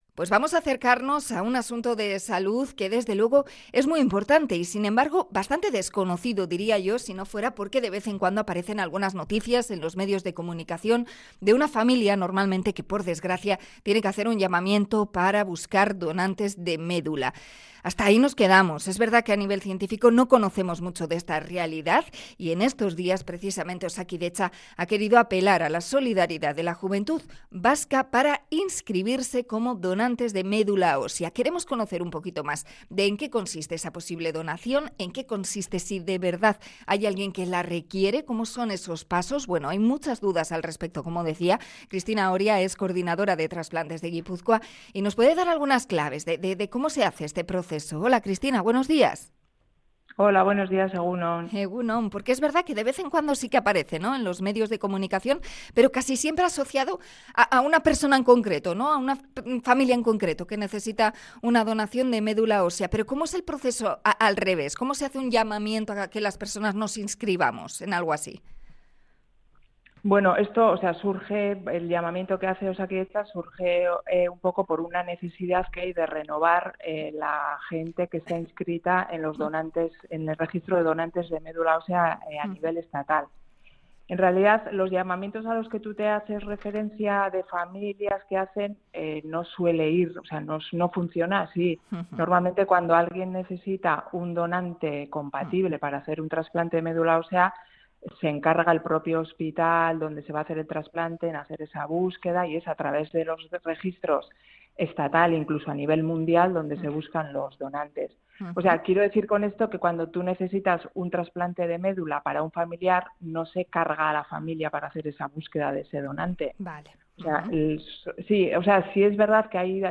Entrevista sobre el registro de donantes de médula